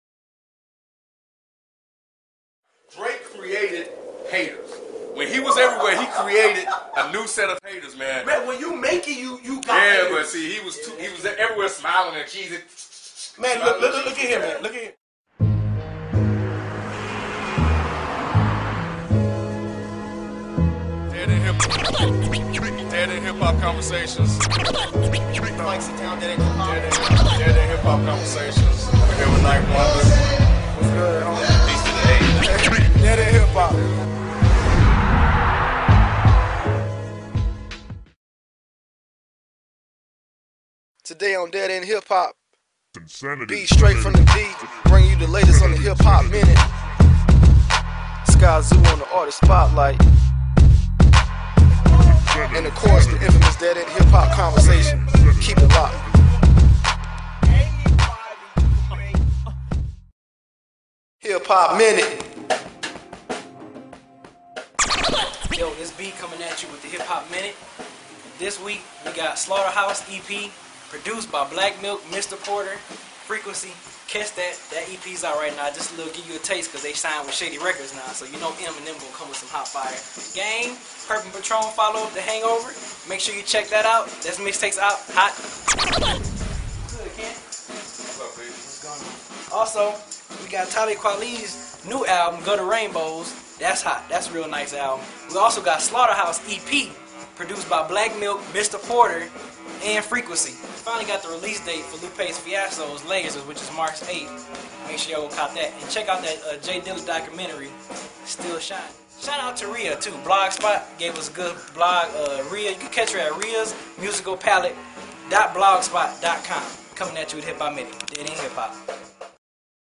Dead End Hip Hop Webisode 2 SKYZOO Interview